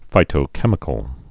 (fītō-kĕmĭ-kəl)